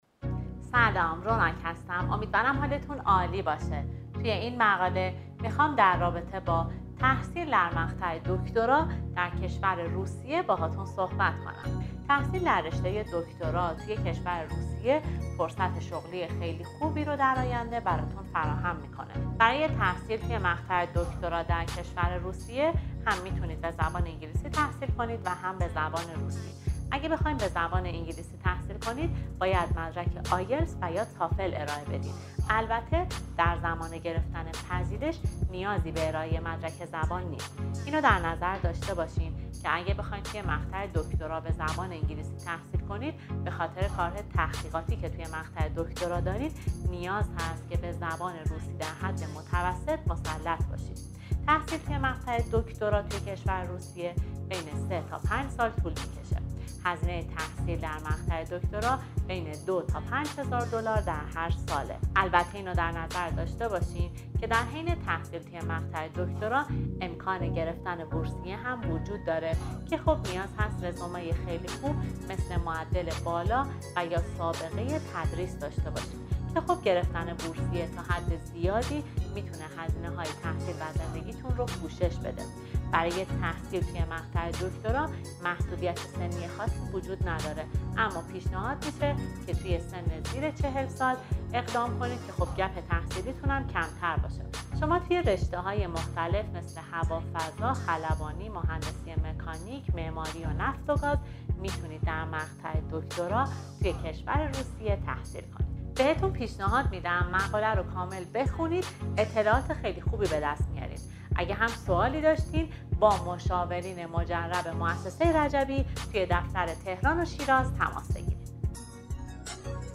پادکست تحصیل دوره دکترا در روسیه